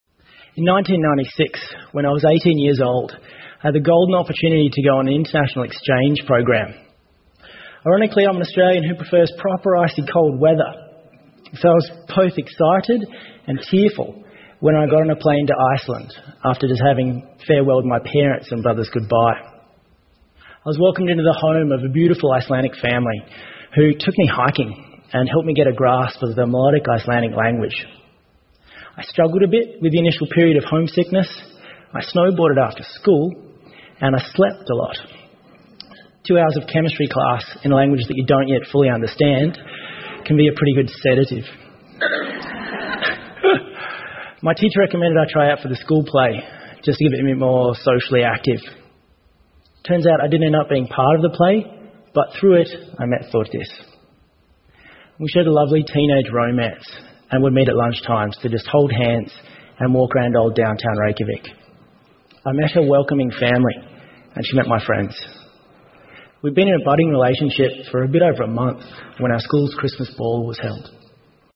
TED演讲:性暴力的救赎之路() 听力文件下载—在线英语听力室